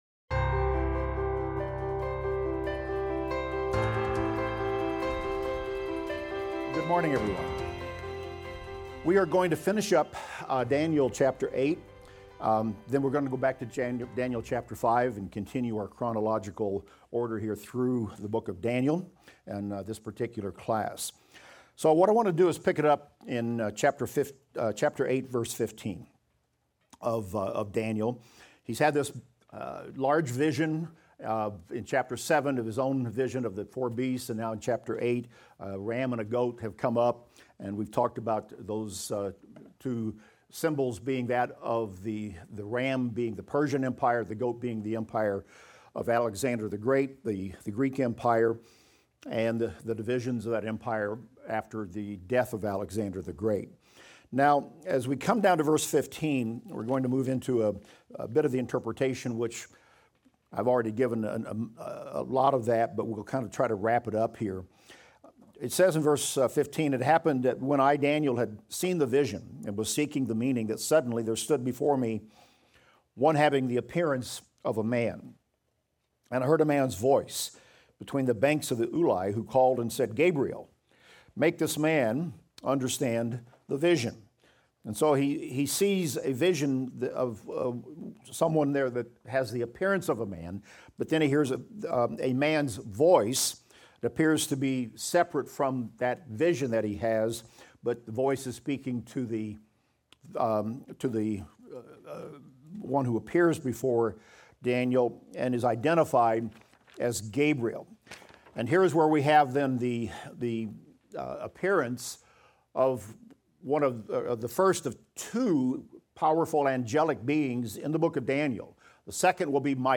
Daniel - Lecture 13 - audio.mp3